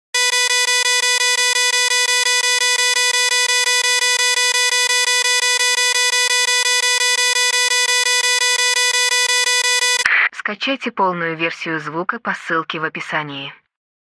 Звук ошибки в Bios
• Качество: Высокое